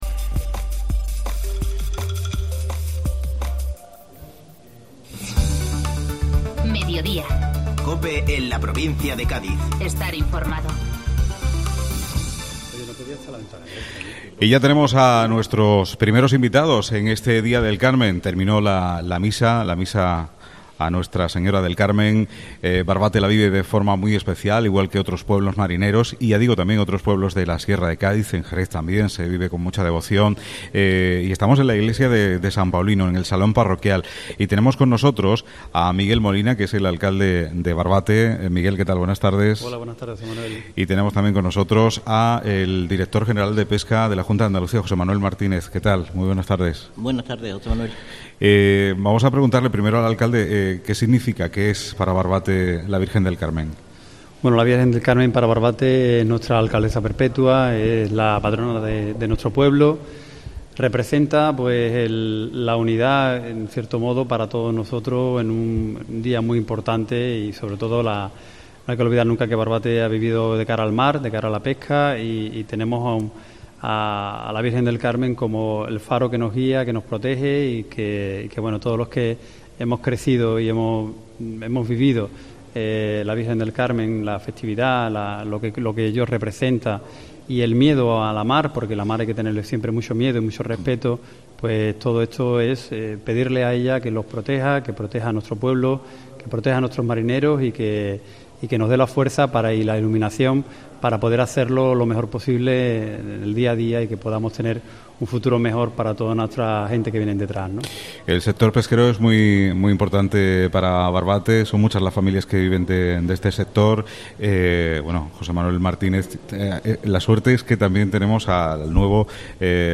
Mediodía COPE Provincia de Cádiz desde Barbate en la festividad de la Virgen del Carmen